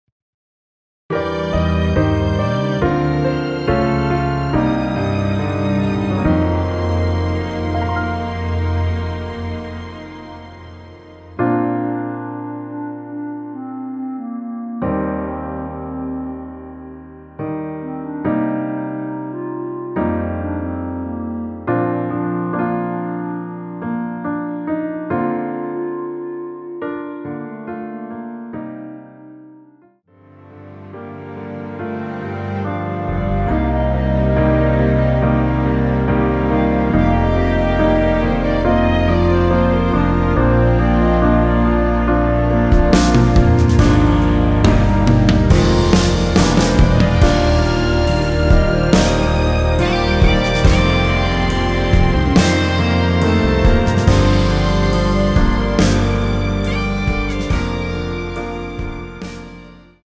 원키에서(-1)내린 멜로디 포함된 MR입니다.(미리듣기 확인)
Bb
앞부분30초, 뒷부분30초씩 편집해서 올려 드리고 있습니다.
중간에 음이 끈어지고 다시 나오는 이유는